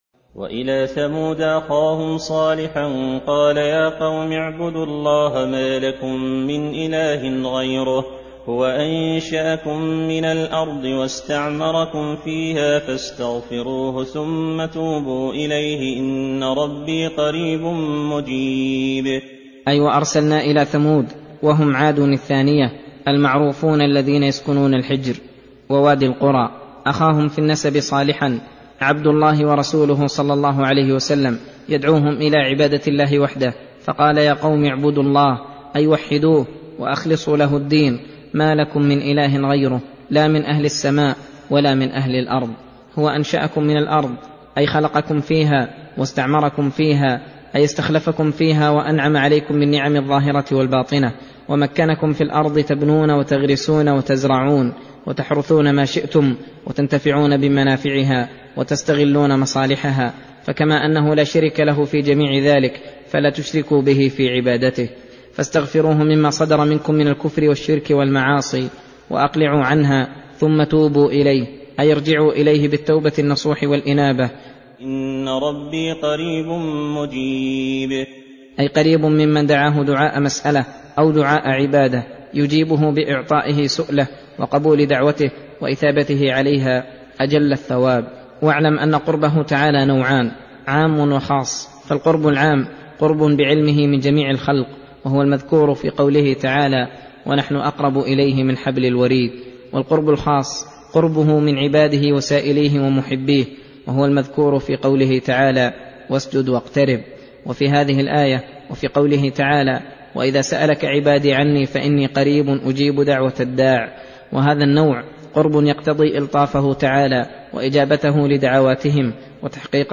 درس (34): تفسير سورة هود : (61 - 87)